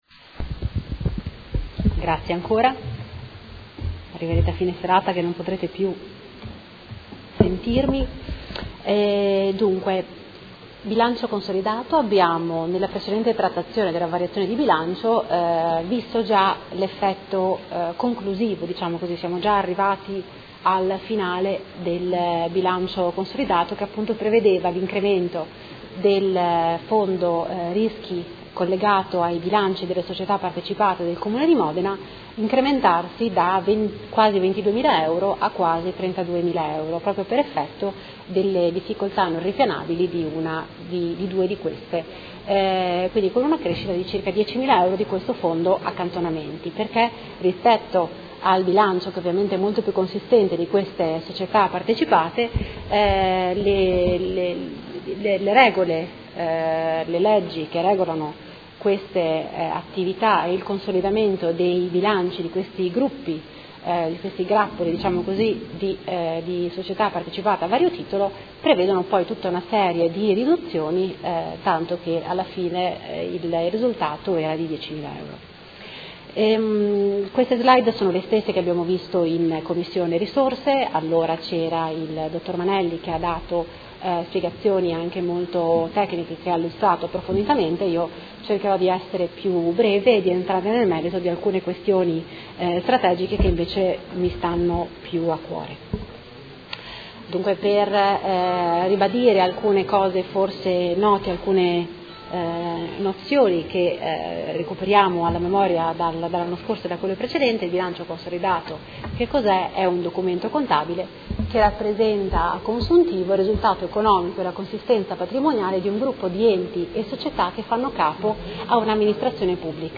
Seduta del 22/09/2016 Proposta di deliberazione: Bilancio Consolidato 2015 del Gruppo Comune di Modena, verifica finale del controllo sulle Società partecipate per l’esercizio 2015 e monitoraggio infrannuale 2016